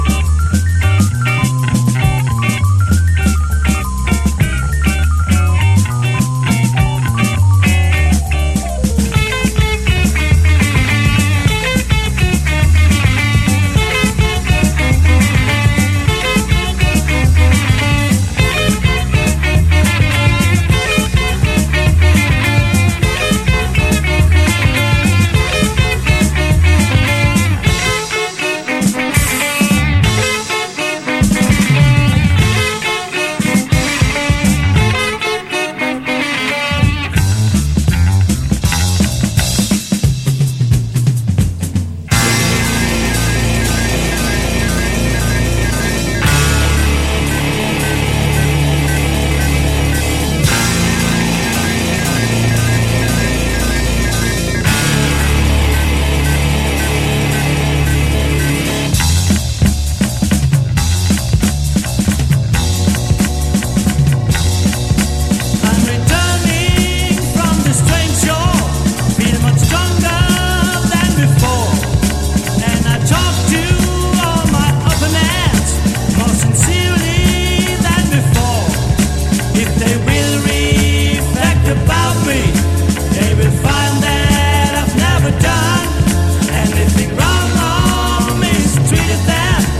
ROCK / PUNK / 80'S～ / GARAGE PUNK